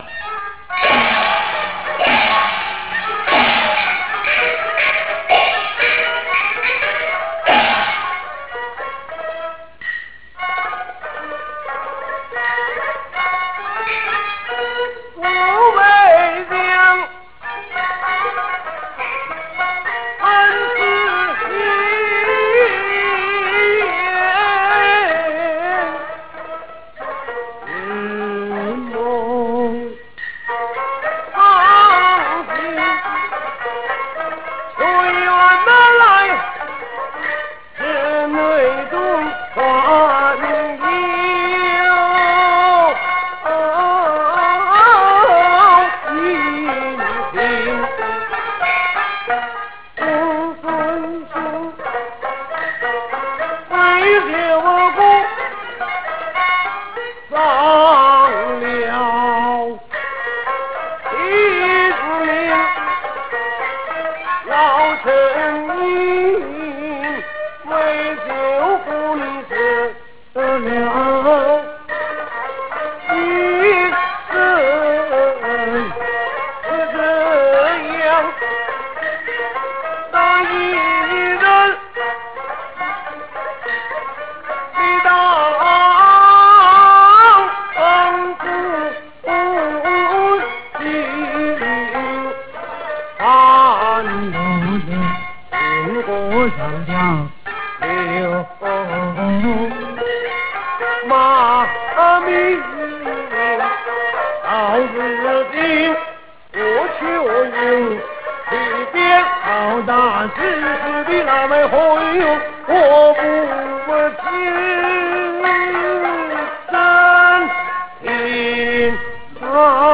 Beijing Opera Arias
But the painted face actor's voice is a resonant and deep sound.
As If Awaken From A Dream: a Jing aria from Orphan of Zhao Family;